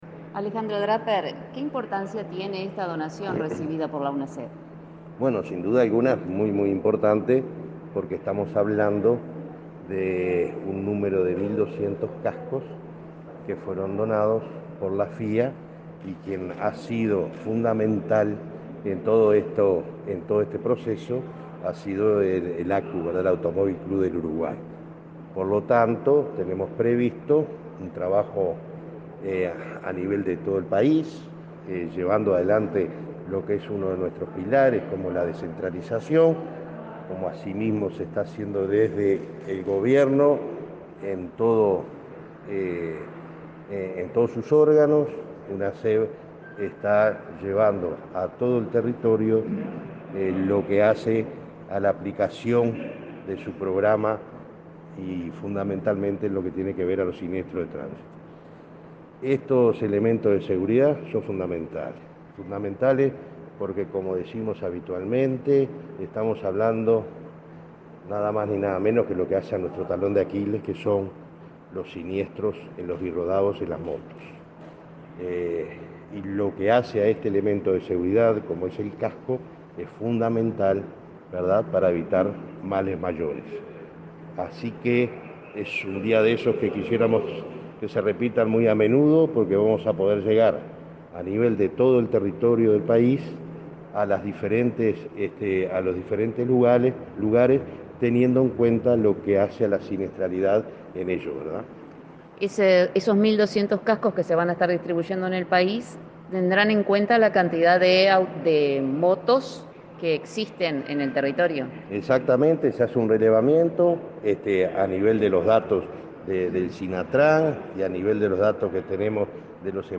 Entrevista al presidente de Unasev, Alejandro Draper